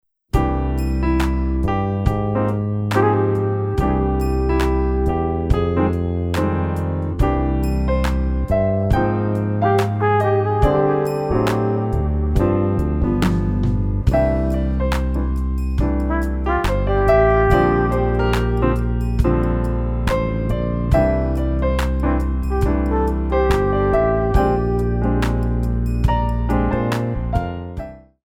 4 bar intro
ballad
Modern / Contemporary